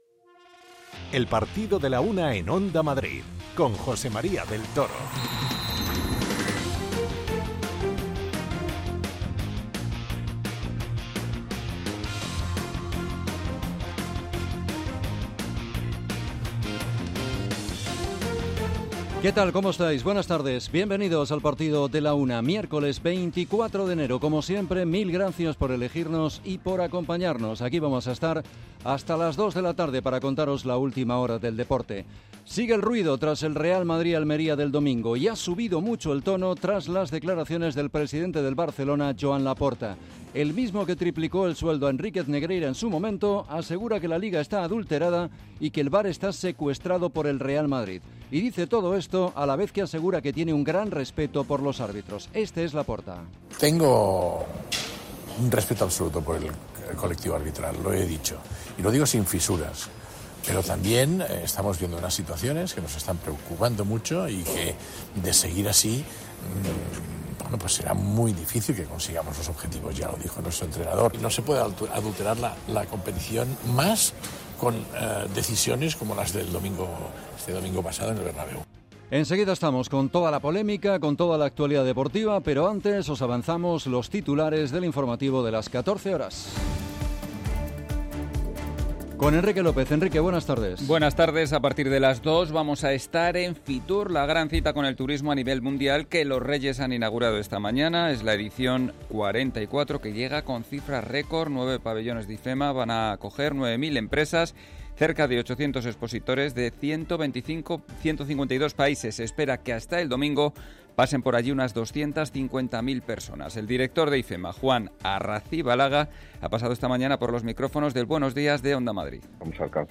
Comenzamos con la previa del Atlético de Madrid- Sevilla de Copa. Escuchamos en directo a Simeone que opina sobre el choque y la situación creada en torno al VAR y la polémica tras el Real Madrid – Almería.